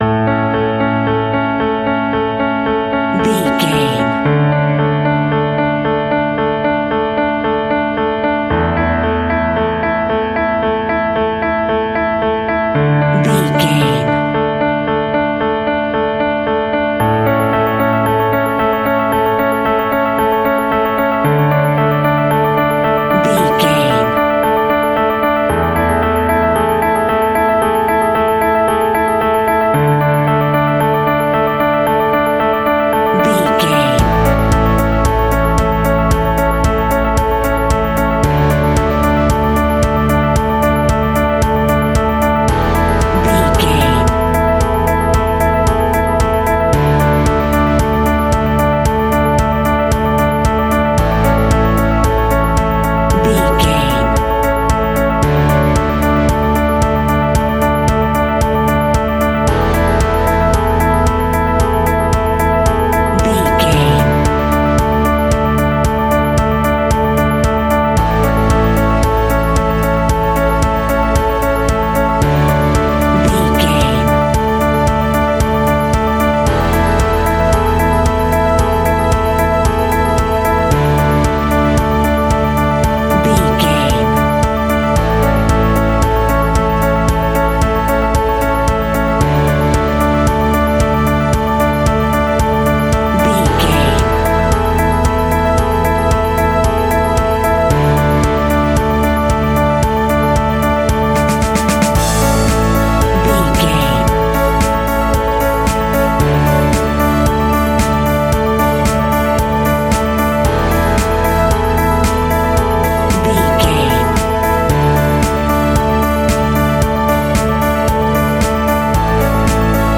Uplifting
Ionian/Major
pop rock
indie pop
fun
energetic
instrumentals
guitars
bass
drums
piano
organ